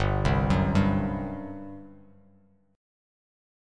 GM7sus4 chord